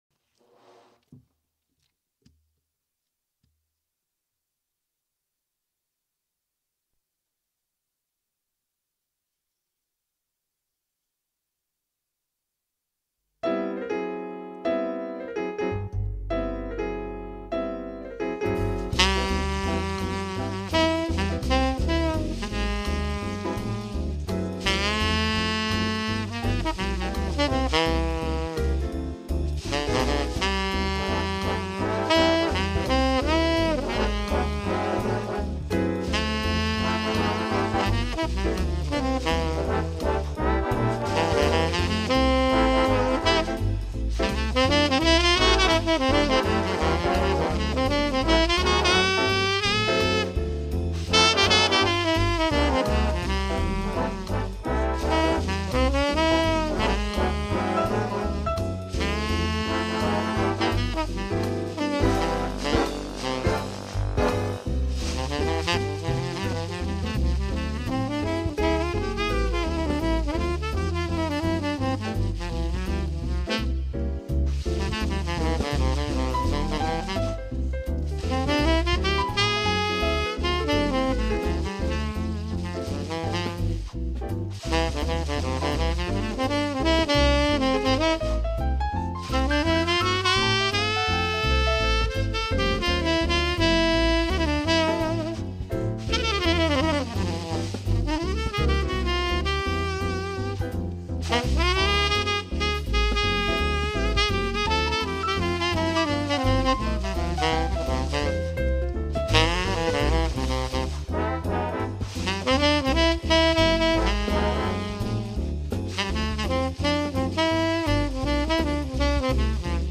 Saxofonunderhållning